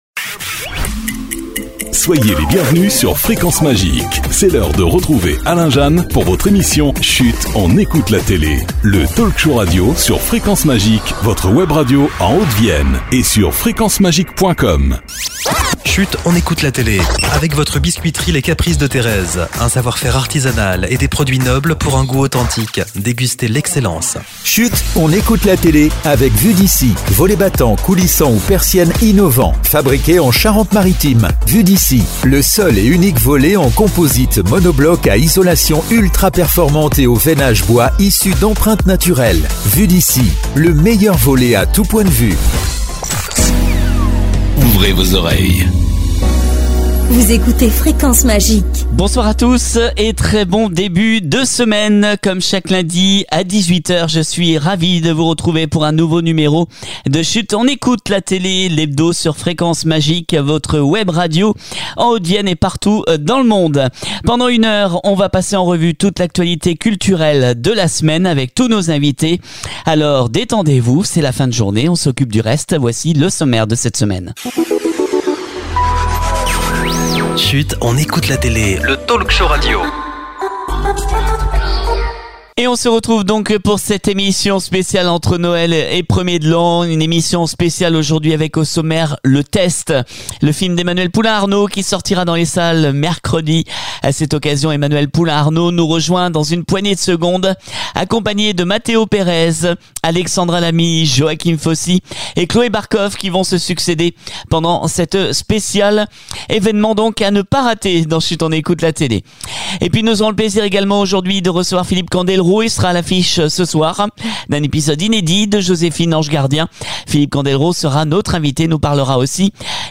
puis on a parlé de légo masters qui fait son retour sur M6 et de son spectacle “Grandis un peu” Eric Antoine était en ligne avec nous pour finir l’année